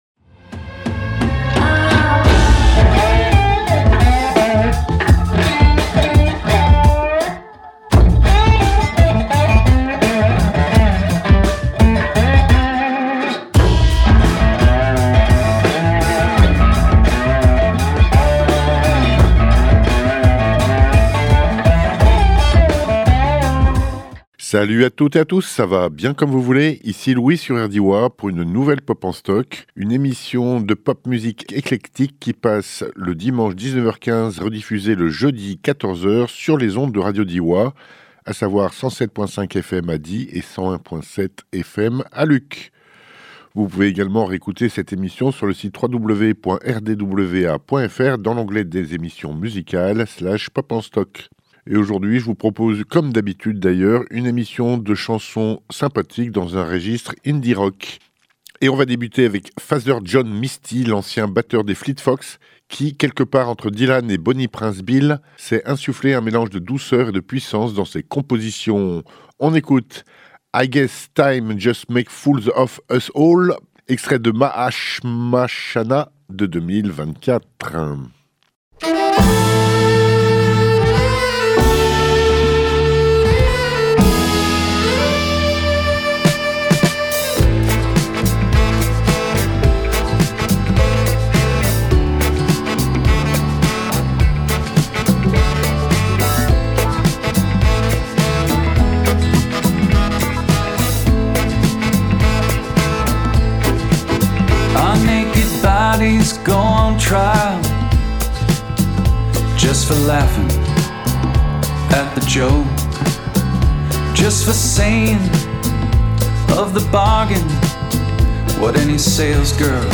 Pop en Stock 573 Indie Rocks Sympas - RDWA - Radio Diois
pop , rock